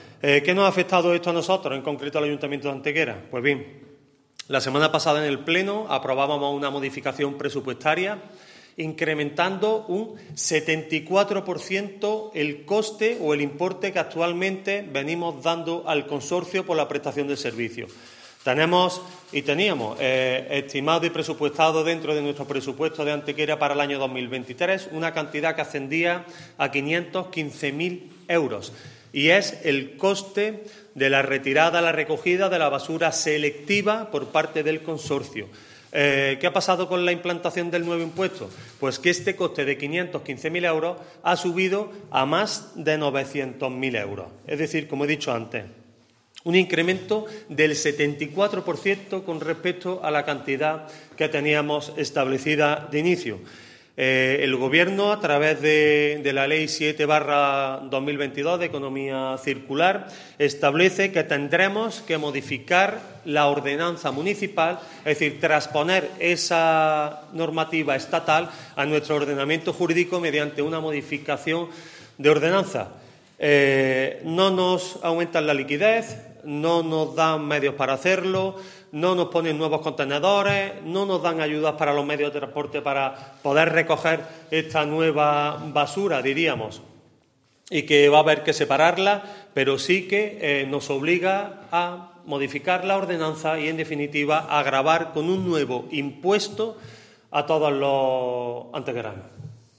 El alcalde de Antequera, Manolo Barón, el teniente de alcalde delegado de Hacienda y Aguas del Torcal, Antonio García Acedo, y el concejal de Medio Ambiente, José Manuel Fernández, han comparecido en rueda de prensa para denunciar públicamente la utilización que esta llevando a cabo el Gobierno de España sobre los ayuntamientos a la hora de obligarlos a actuar como “meros recaudadores de impuestos” en relación a la creación del nuevo Impuesto sobre el Depósito de Residuos en Vertederos, la Incineración y la Coincineración (IDRV).
Cortes de voz